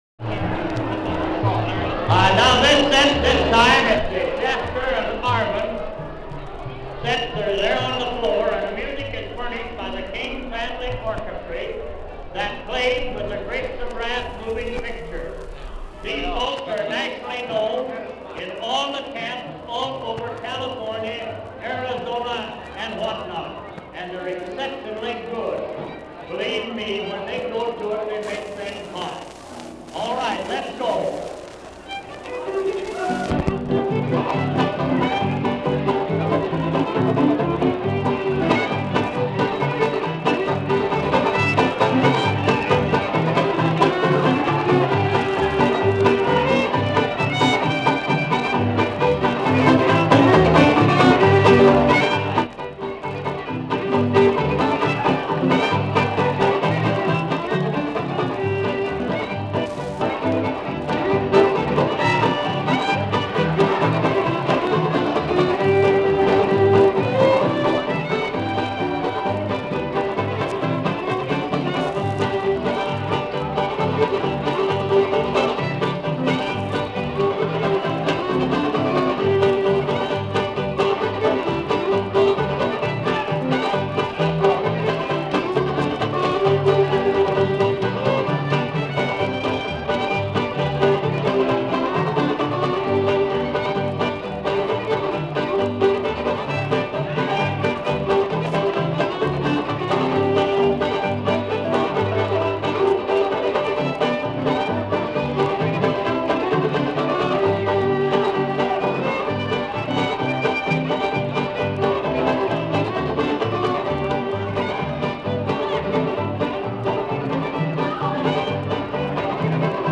絃樂器(Stringed Instruments)
您可以聆聽這段利用葫蘆樂器所演奏的音樂錄音；這些樂器的聲音聽起來跟專業製樂器有什麼不同？
In country and bluegrass music, the band plays stringed instruments like the guitar, banjo, fiddle, stand-up bass, and mandolin, but did you know that years ago, a band might play these same instruments made out of gourds?
Although the King family in this photo is playing regular instruments, they also played on home-made gourd instruments, which you can hear in this sound recording.
AUDIO CREDIT: King family, performers.